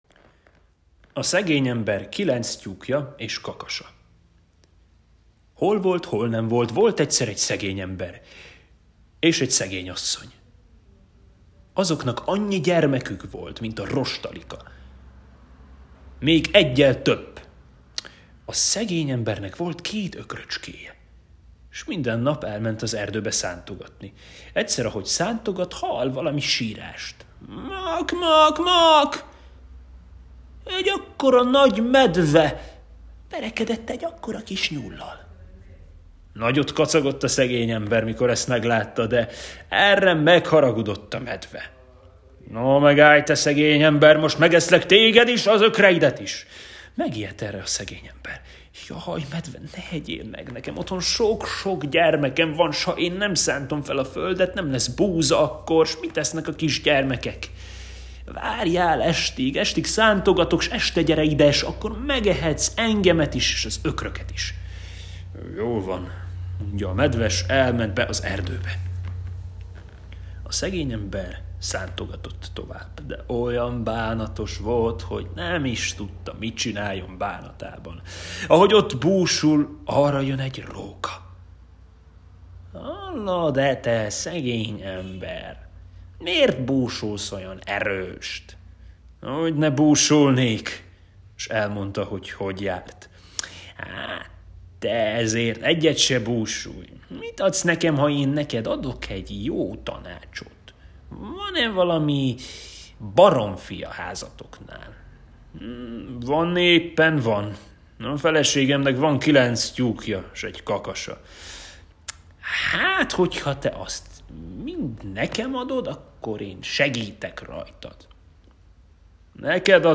Hangos mesék